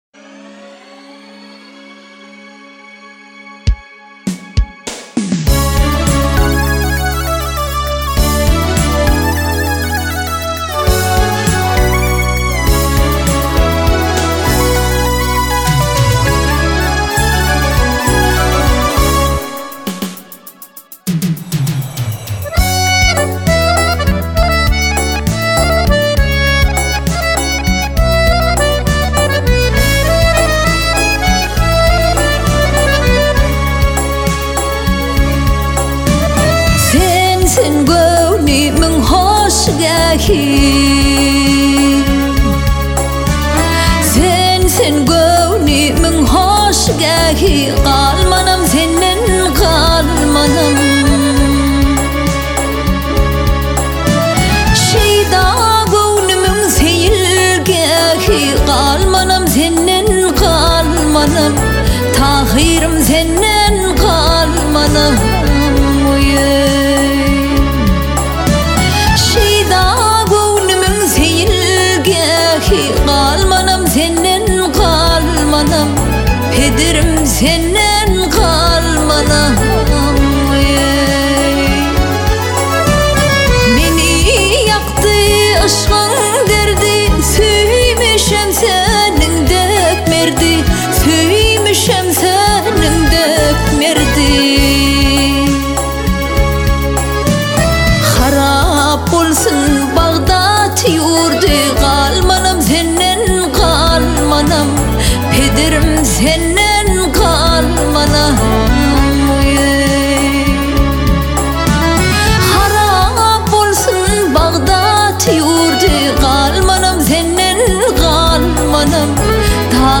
Halk aýdymy